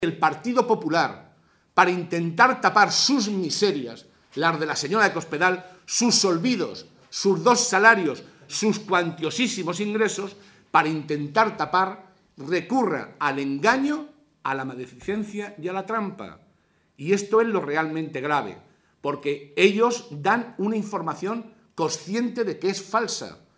José Molina, portavoz del Grupo Parlamentario Socialista
Cortes de audio de la rueda de prensa